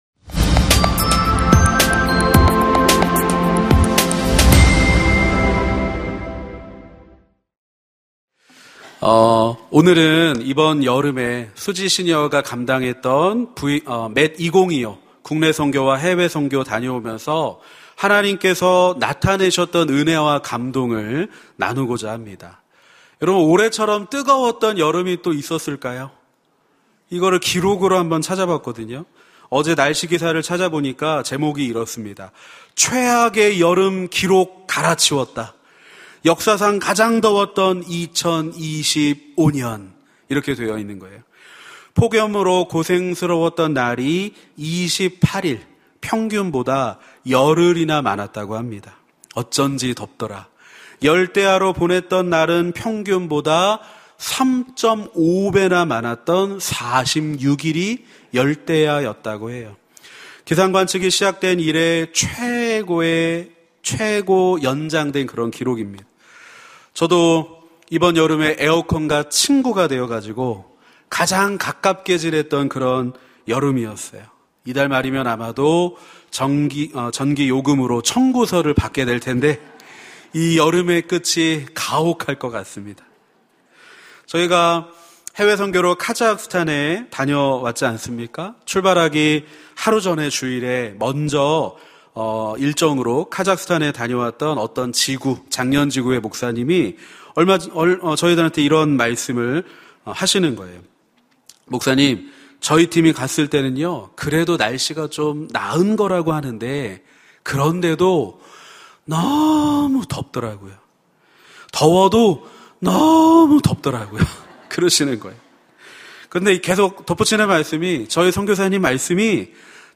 설교 : 시니어예배